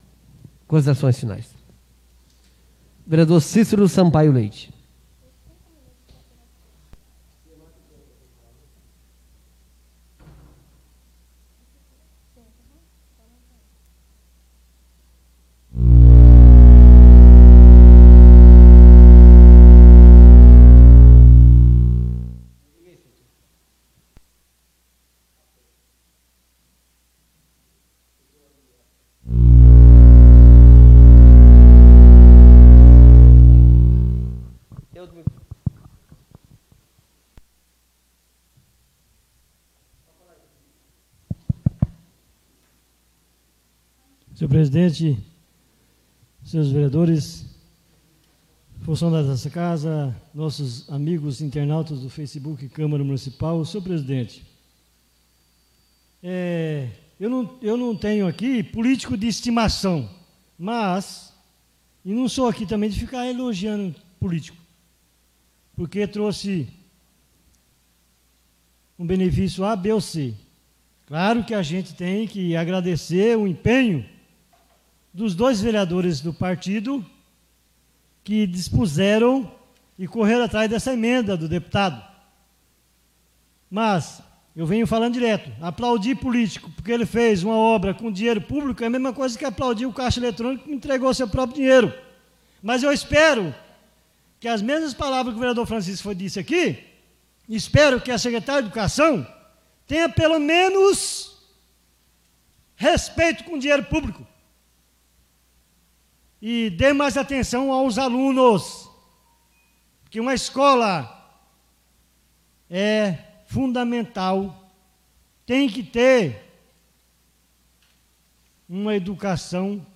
Oradores das Explicações Pessoais (22ª Ordinária da 4ª Sessão Legislativa da 6ª Legislatura)